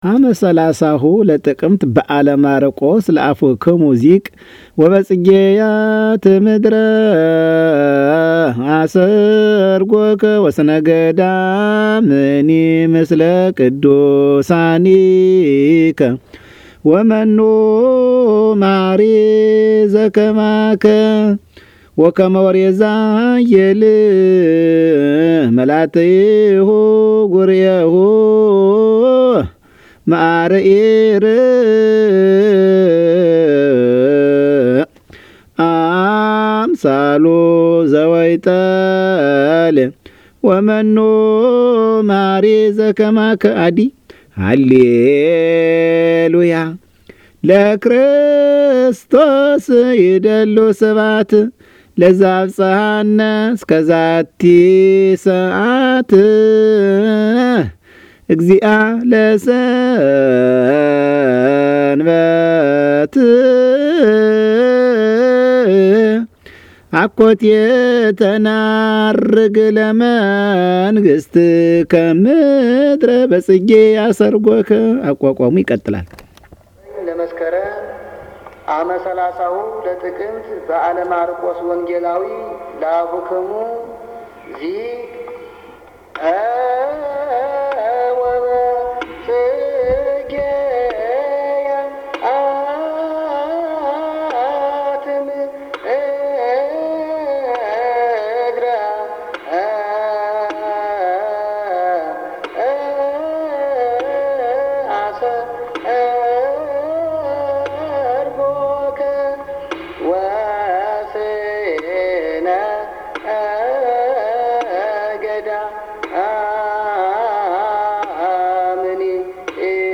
1 kum and aqua zema.MP3